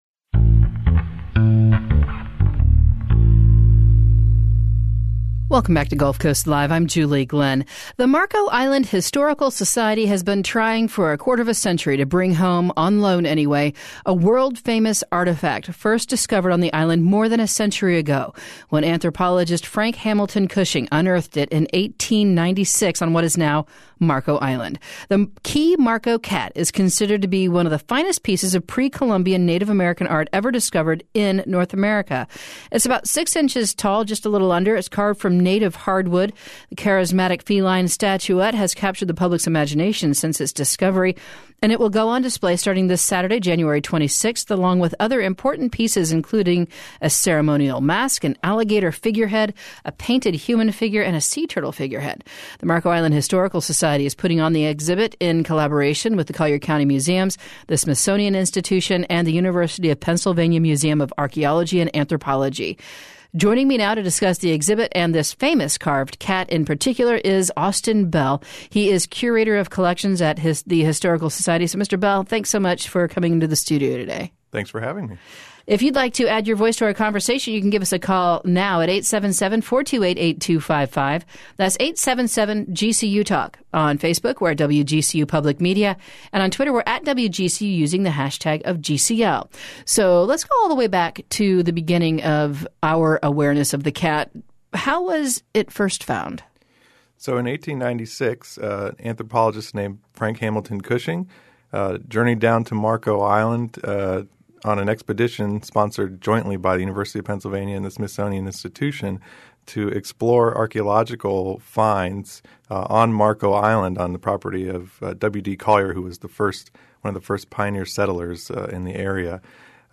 joins us in the studio to learn more about the exhibit and the Key Marco Cat.